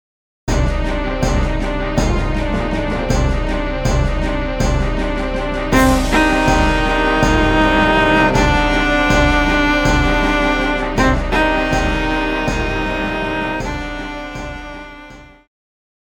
Viola
Band
Instrumental
World Music,Electronic Music
Only backing